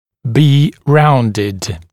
[biː ‘raundɪd][би: ‘раундид]быть округленным (напр. о числе, значении, гранях дуги и пр.))